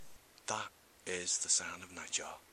Nightjars - The Sound of a Nightjar
Category: Sound FX   Right: Personal